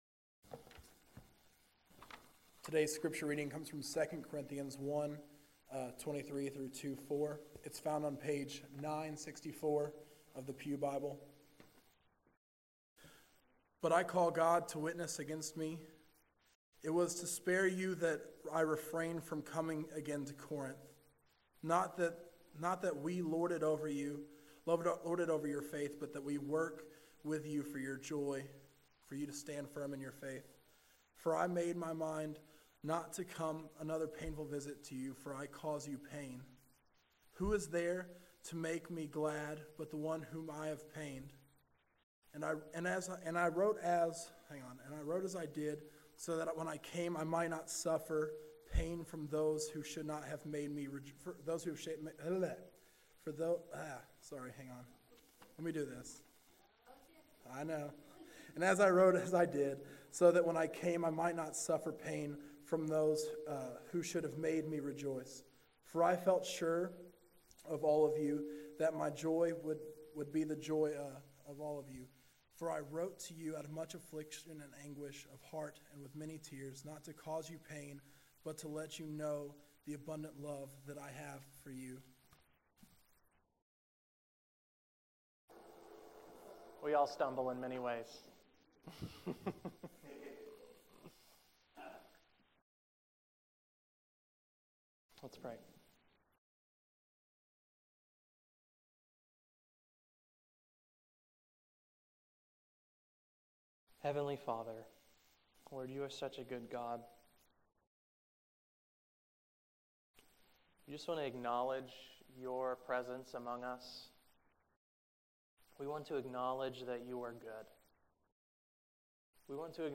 2 Corinthians 1:23-2:4 Today’s message was the next in a series through the book of 2nd Corinthians.